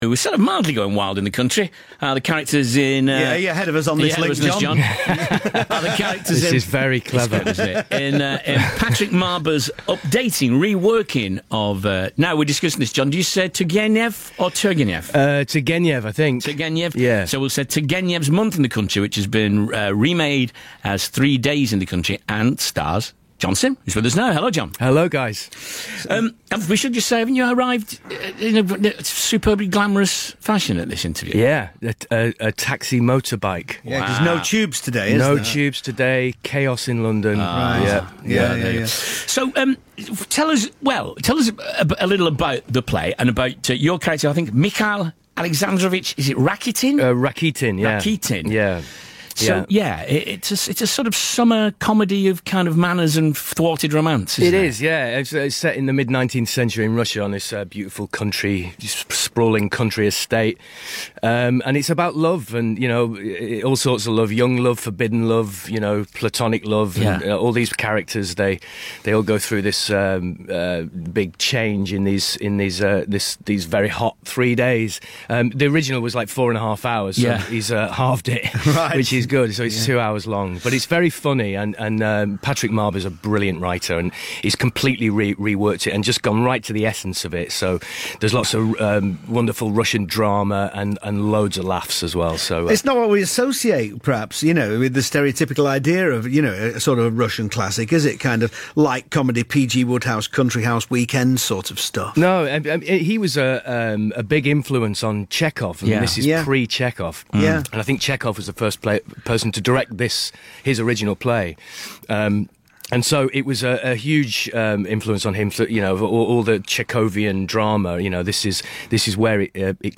Radio Interview: John Simm chats with Radcliffe and Maconie on BBC Radio 6